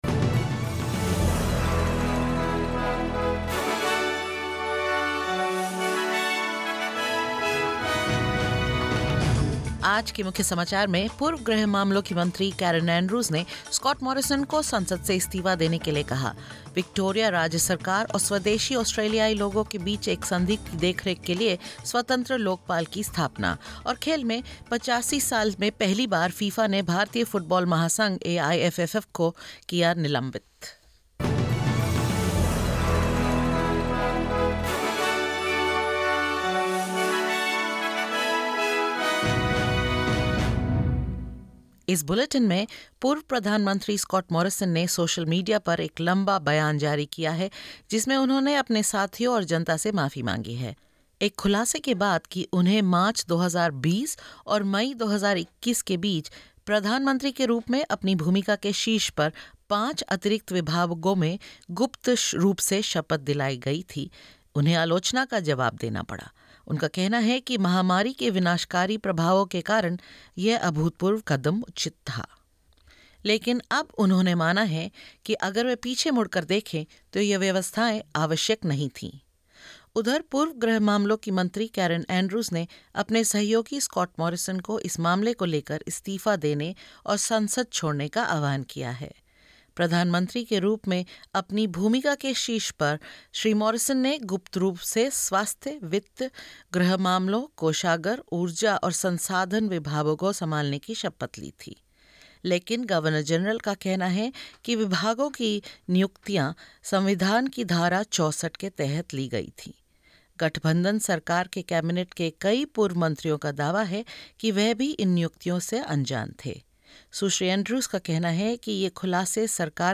In this latest SBS Hindi bulletin: Former home affairs minister Karen Andrews urges Scott Morrison to resign over secretly held portfolios; New South Wales cracks down on ministers' future job prospects after an independent review report; And in sport FIFA bans All India Football Federation (AIFF) over 'third party influence' and more.